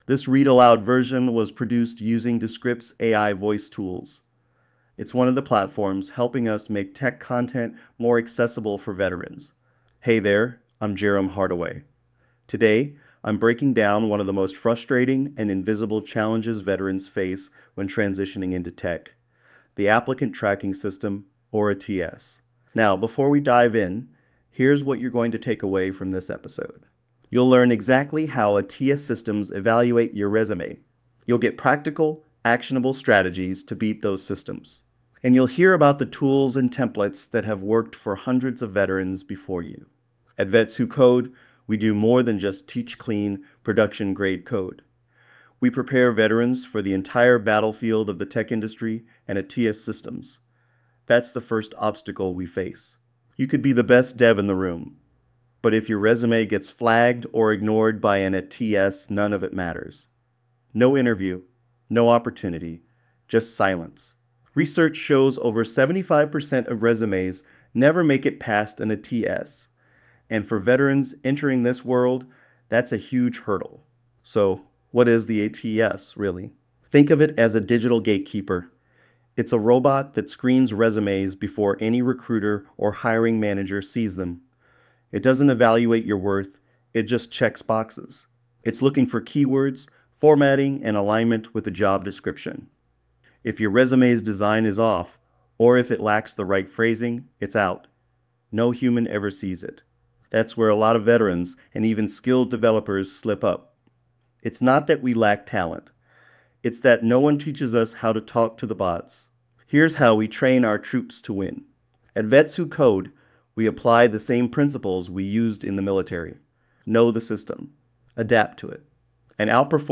🎧 Beat the Bots Audio Brief